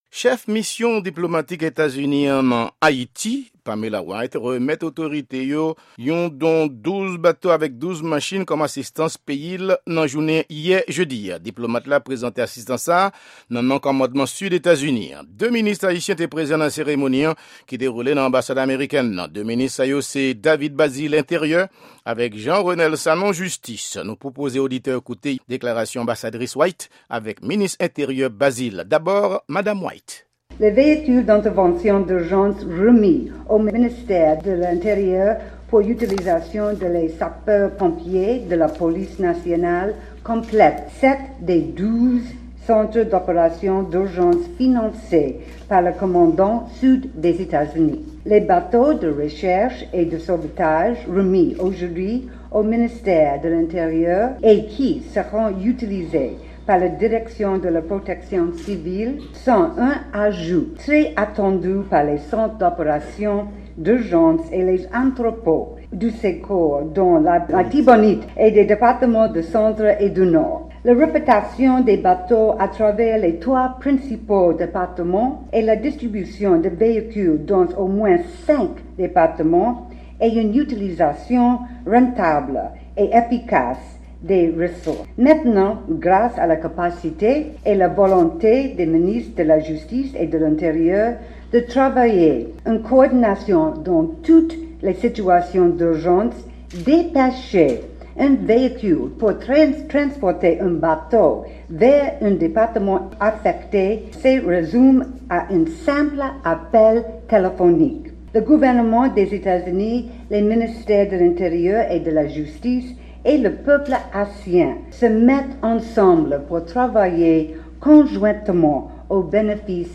Repòtaj sou Kado Etazini pou Ayiti : 12 bato, 12 machin